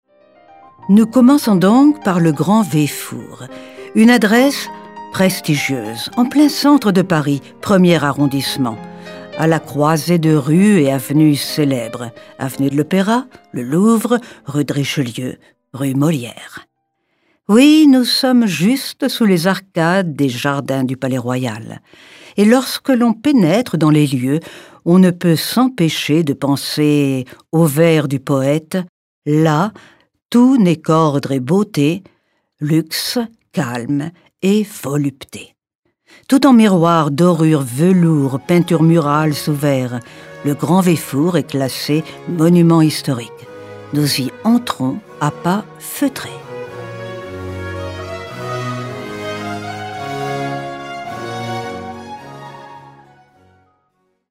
Erfahrene warme französische Stimme.
Kein Dialekt
Sprechprobe: Werbung (Muttersprache):